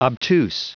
Prononciation du mot obtuse en anglais (fichier audio)
Prononciation du mot : obtuse